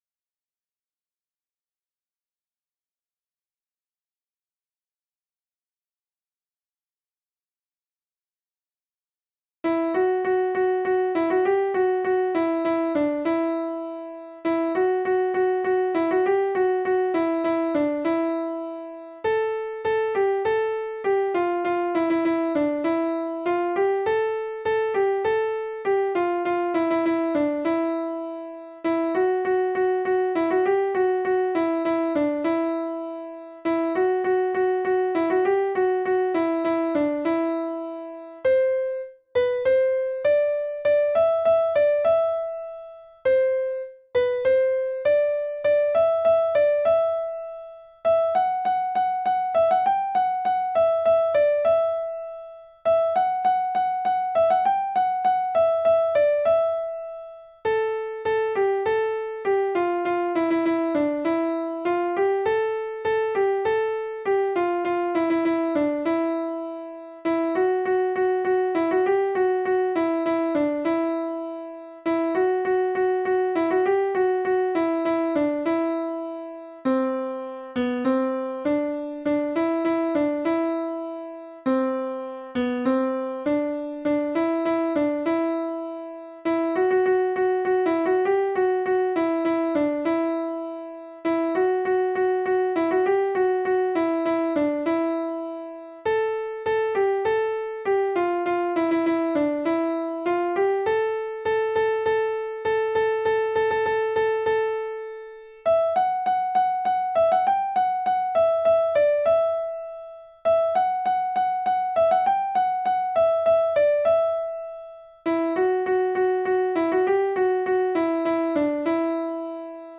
Soprano (version piano